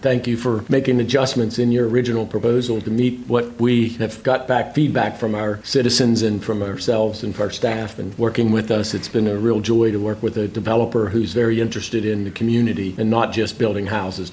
During last night’s Allegany County Commissioner meeting, the commissioners approved the development agreement with D.R. Horton to build 65 homes in the old Allegany High School neighborhood.
Commissioner Bill Atkinson thanked D.R. Horton for working with the County…